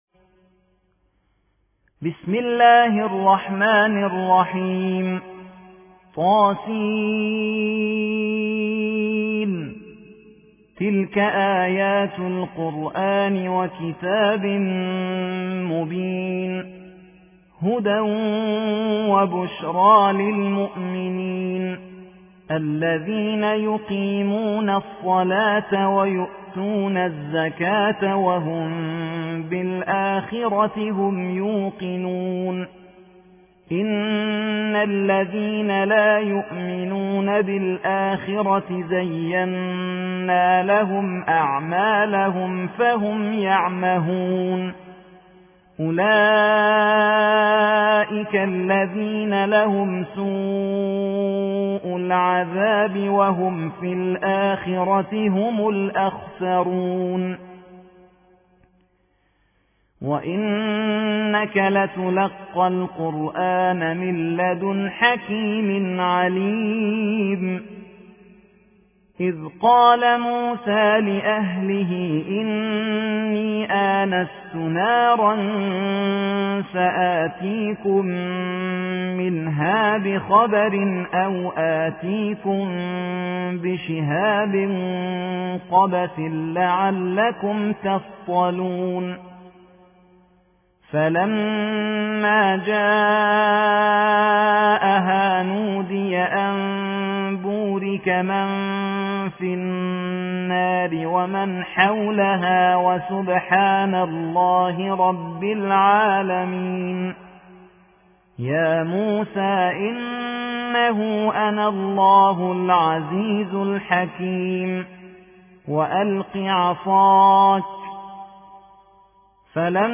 27. سورة النمل / القارئ